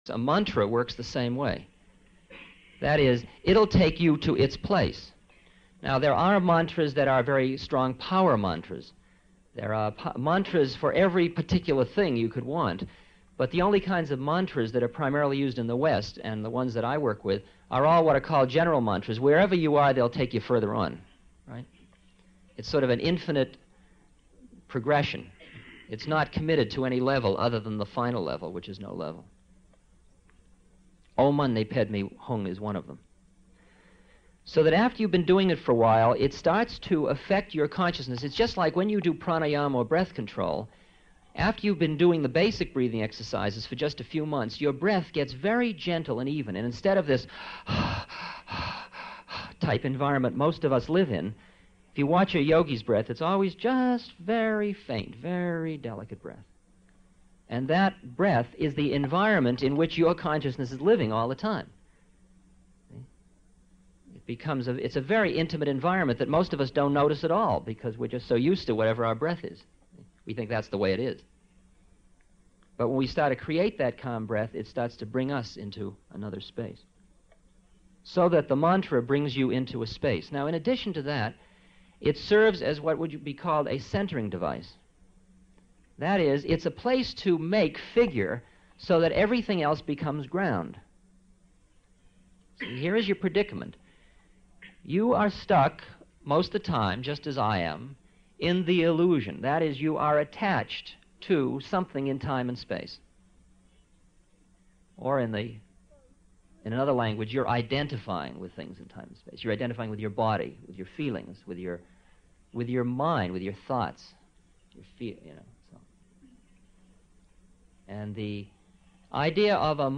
Listen to Ram Dass explain mantras in the three-minute audio clip below, followed by a short article on the power of mantra.